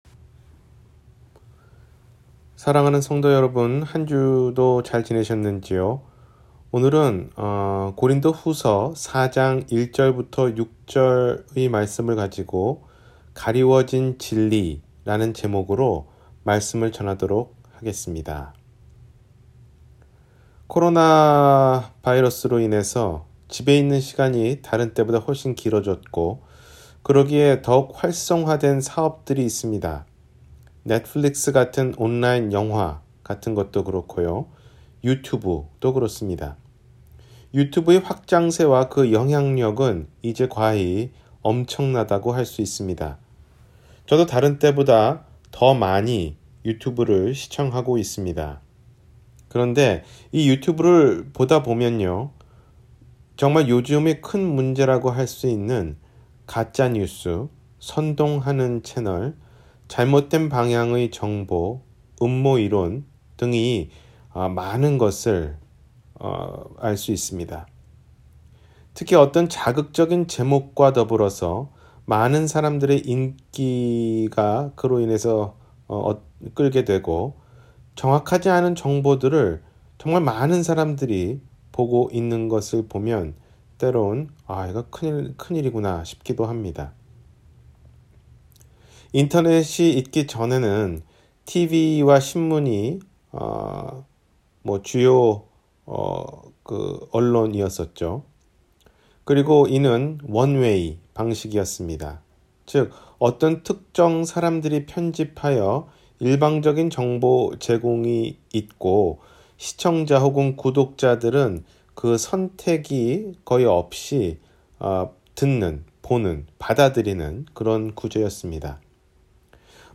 가리워진 진리-주일설교